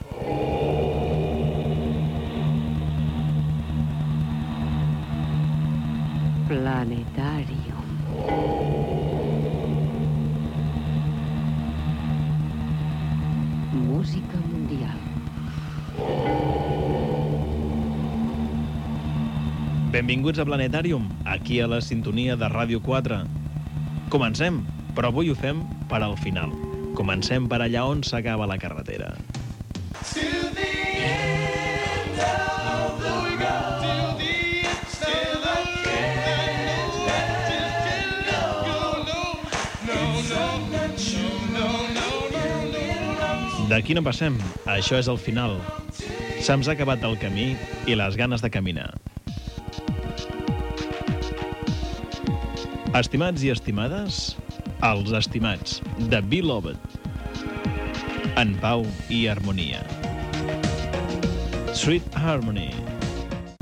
Sintonia i presentació de temes musicals.
Musical